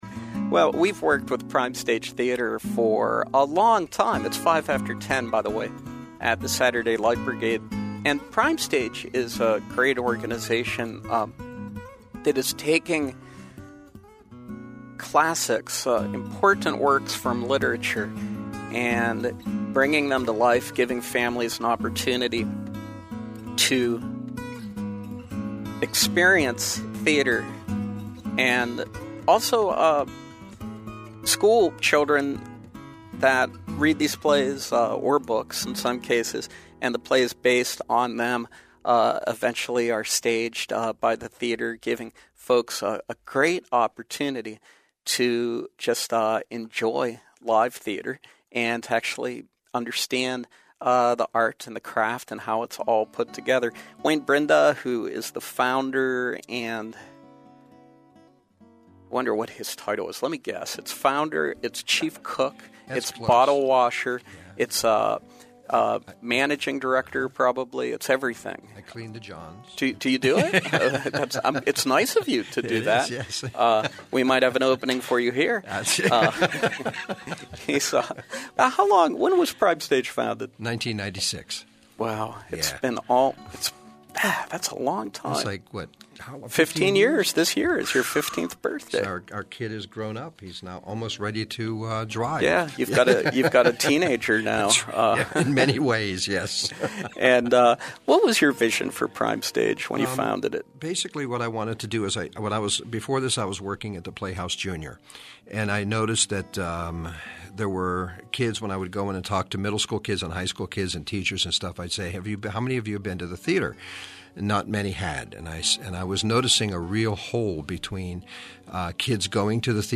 We’re joined by members of Prime Stage Theater as they preview their upcominig performance of The Glass Menagerie.